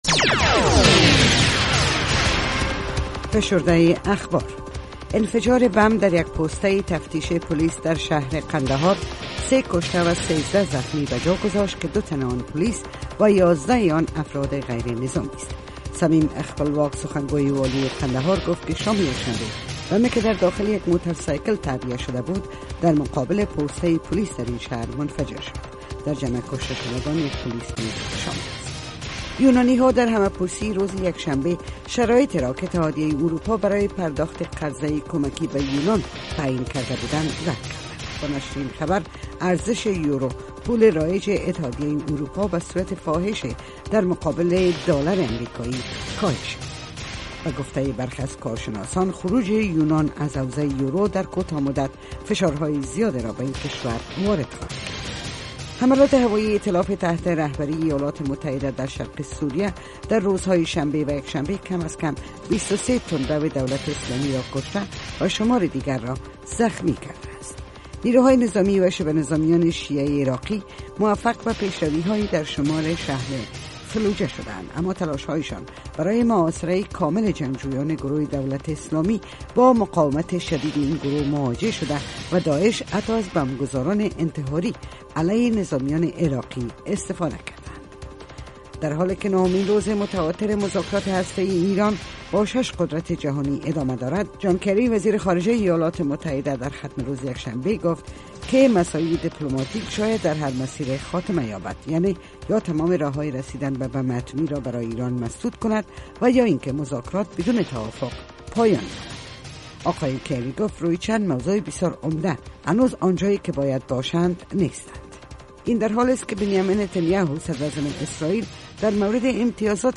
فشرده خبرهای افغانستان و جهان در سه دقیقه